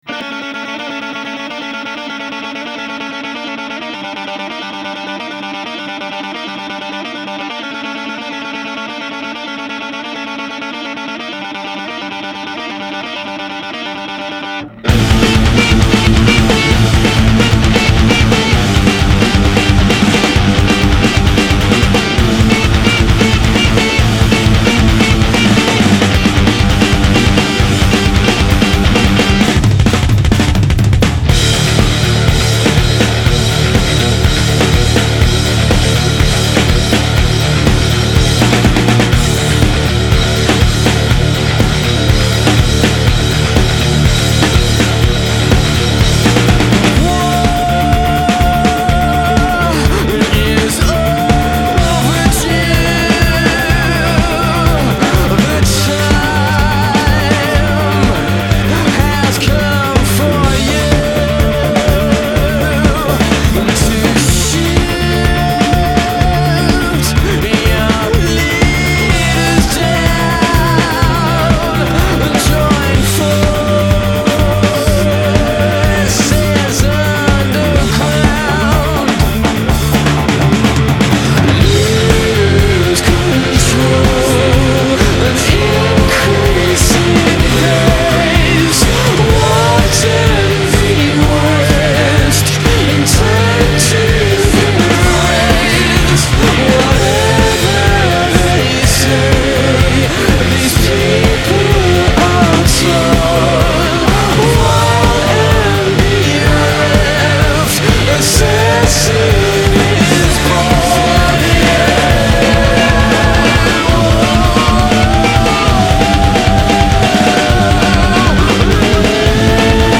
Рок Альтернативный рок Alternative